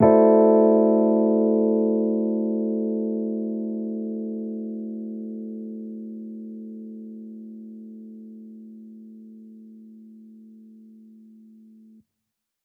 Index of /musicradar/jazz-keys-samples/Chord Hits/Electric Piano 2
JK_ElPiano2_Chord-Am13.wav